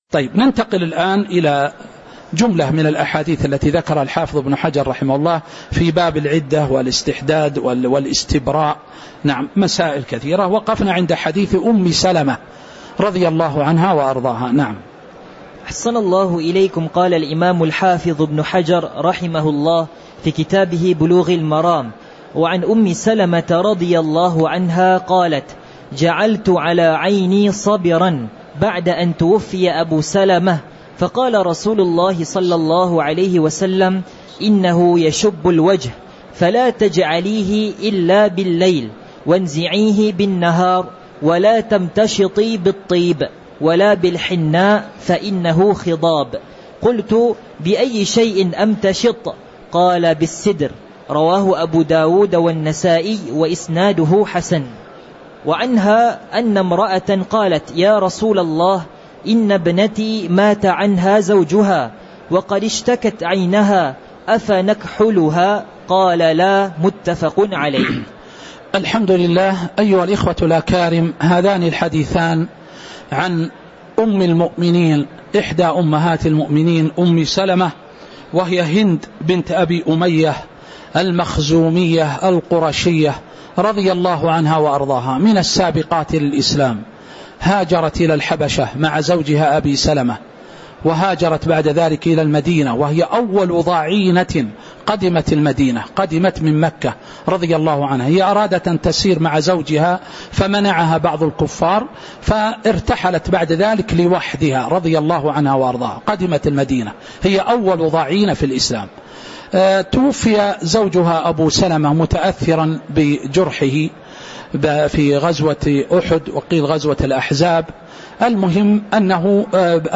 تاريخ النشر ١٤ ذو القعدة ١٤٤٦ هـ المكان: المسجد النبوي الشيخ